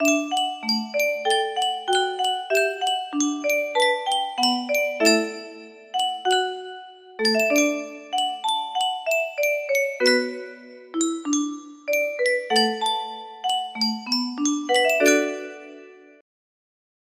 Yunsheng Music Box - Unknown Tune 2424
Full range 60